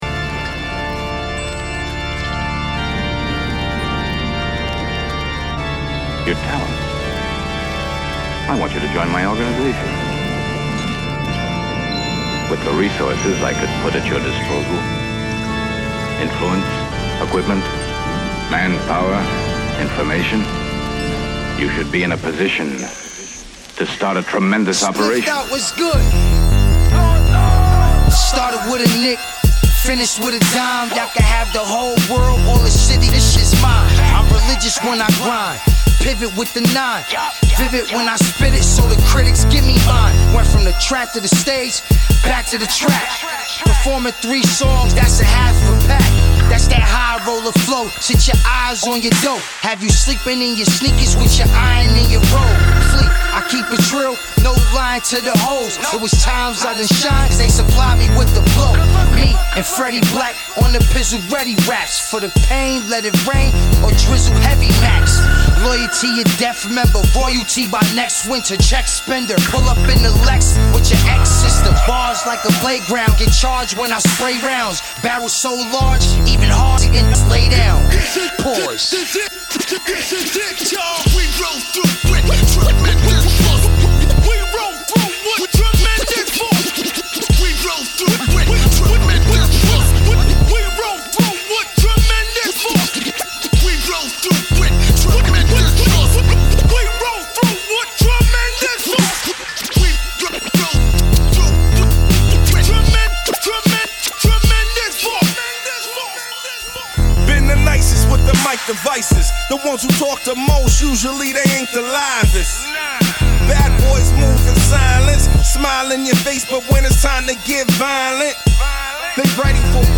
hard hitting, foreboding
Hiphop